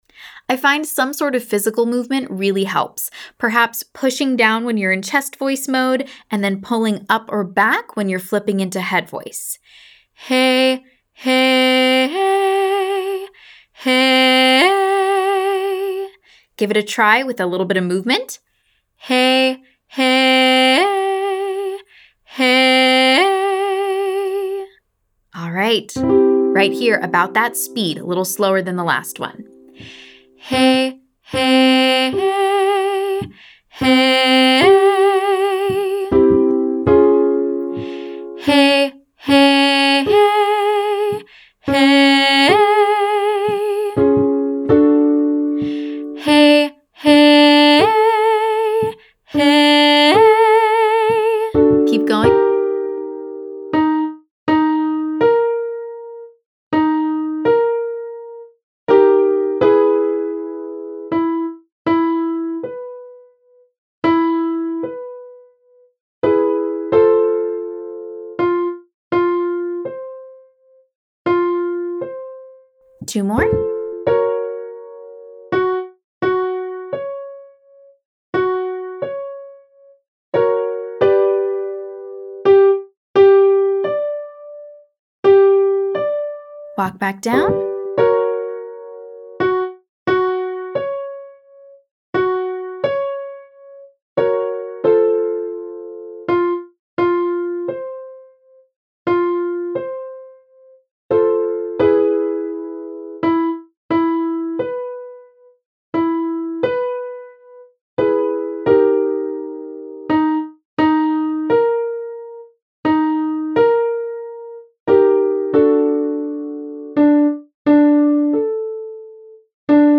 A pop flip, also called a falsetto flip or yodel, is where that abrupt register shift happens on the same syllable or vowel.
Exercise 2: HEY 1 1 hey 5, HE-ey 15